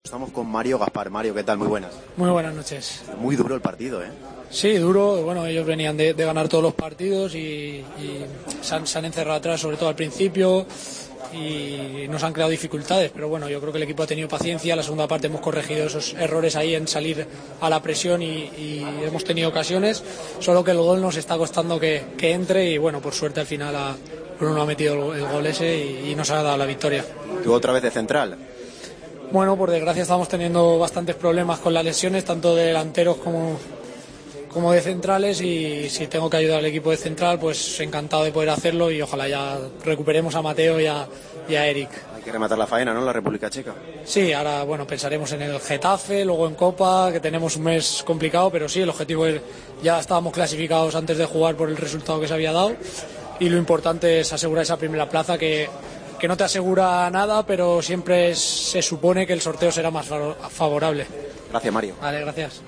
en la zona mixta de El Madrigal